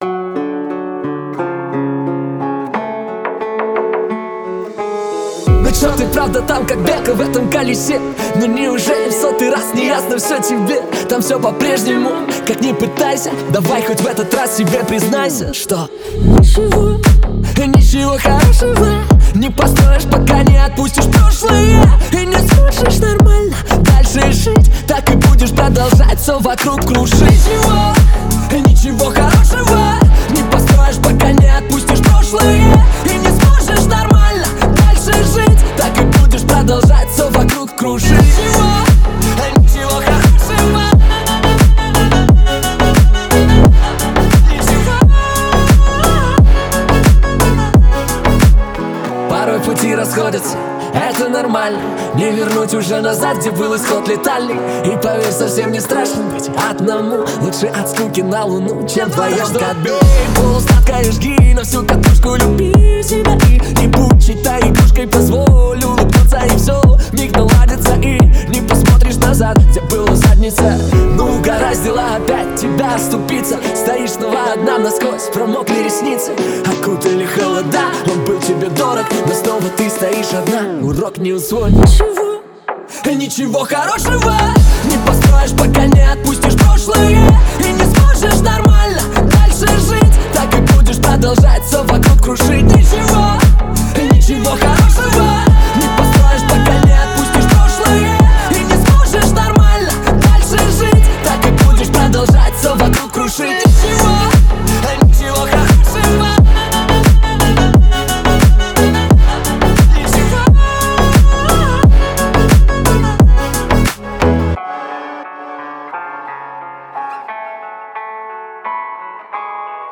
Танцевальная музыка
музыка для танцев
танцевальные песни Размер файла